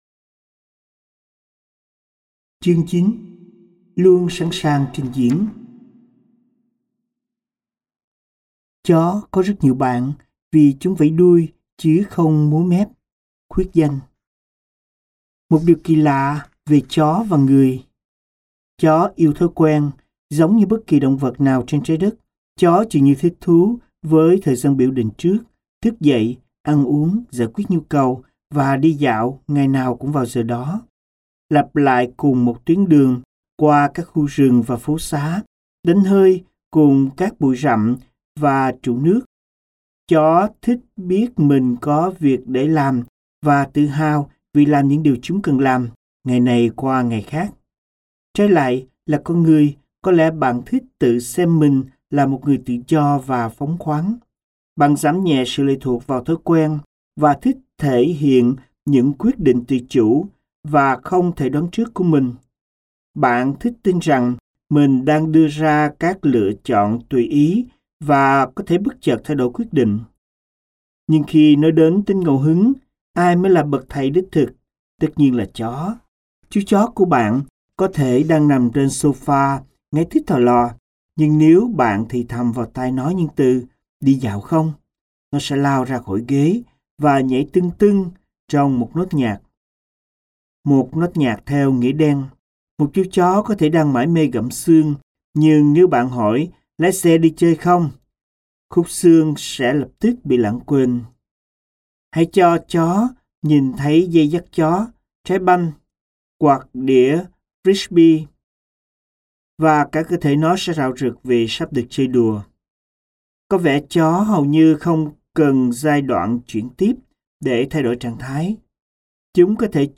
Sách nói Lắng Nghe Như Một Chú Chó Và Tạo Dấu Ấn Của Bạn Với Thế Giới - Kỹ Năng Cuộc Sống - Sách Nói Online Hay